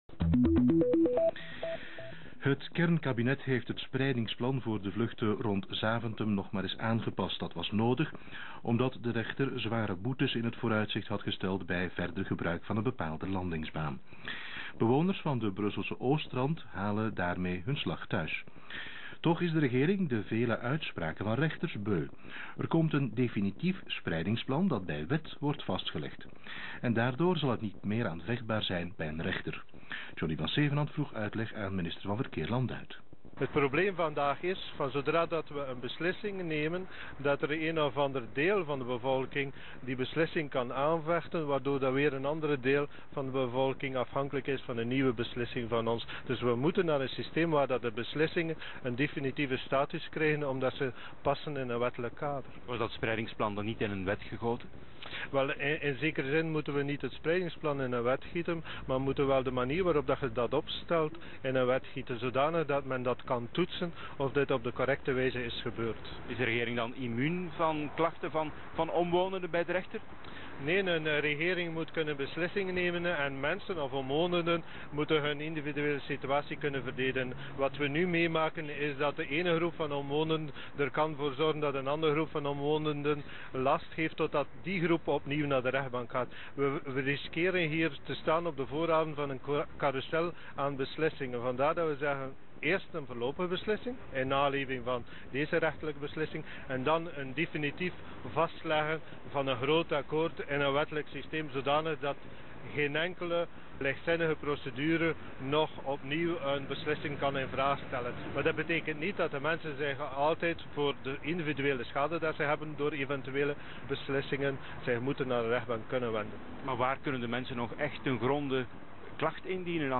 In het radio1-journaal van 18 april '05 lichtte hij zijn beleid toe: 'spreiden tot we allemaal ziek worden!